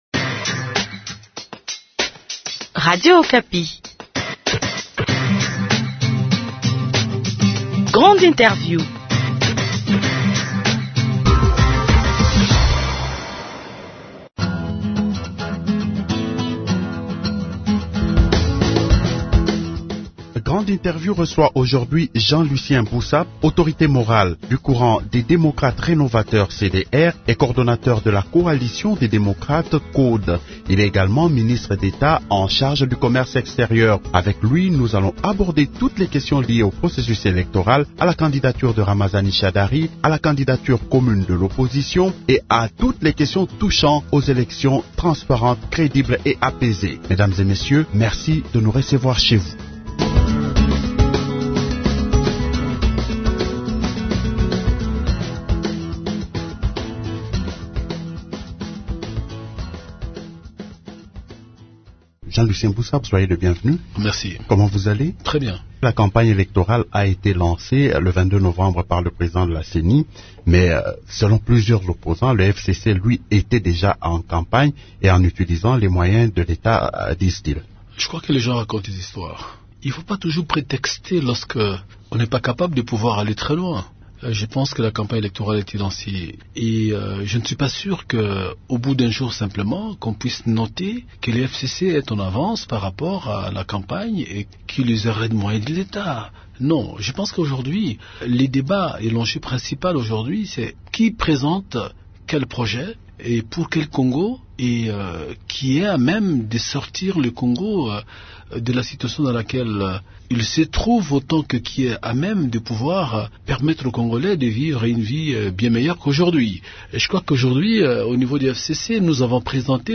L’invité de Grande Interview est Jean-Lucien Bussa, autorité morale du courant des Démocrates Rénovateurs « CDER » et coordonnateur de la coalition des démocrates « CODE », membres du Front commun pour le Congo.